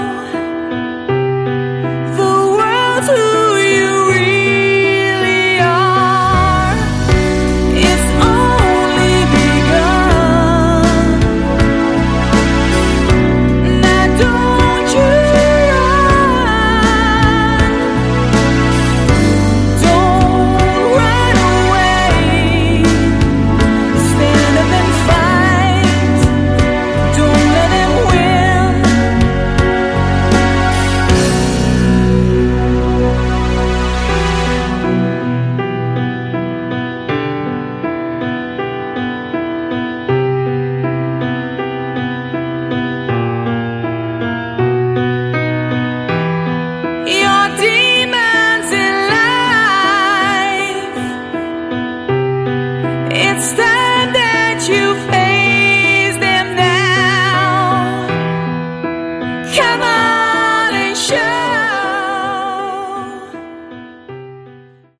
Category: AOR
is another excellent mid-tempo song